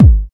drum26.mp3